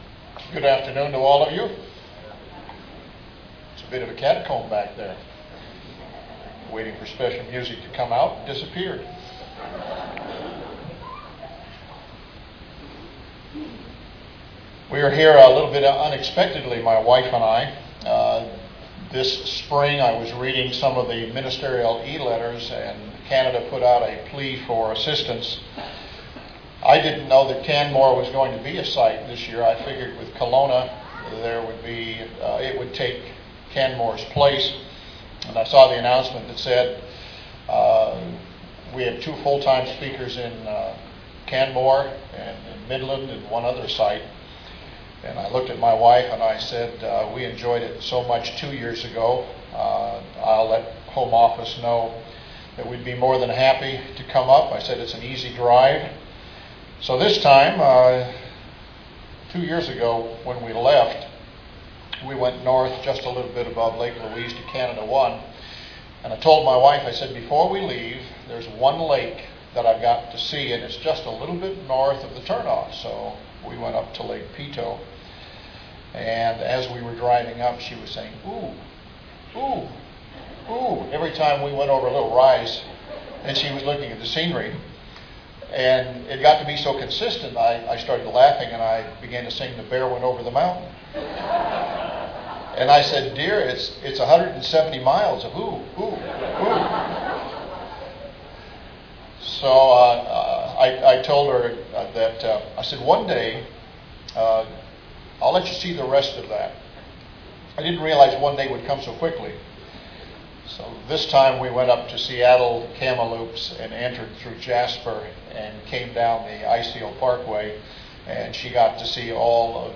This sermon was given at the Canmore, Alberta 2014 Feast site.